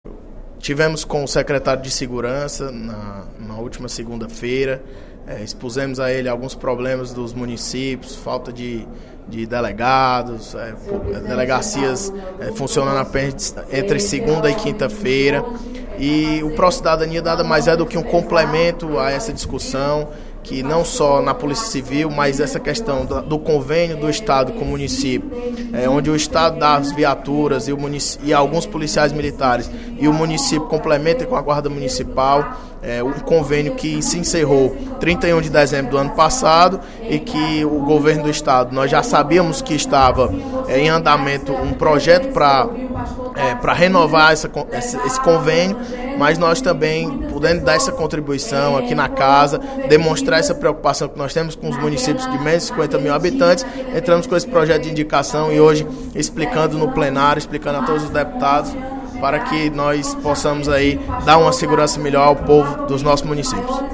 O deputado Bruno Pedrosa (PSC) destacou, durante o primeiro expediente da sessão plenária desta quarta-feira (25/02), o projeto de indicação 19/15, de sua autoria, lido no expediente desta manhã, o qual propõe alterações na lei nº 14.318. A matéria estabelece o retorno do Programa de Proteção à Cidadania, o Pró-Cidadania, com novos prazos.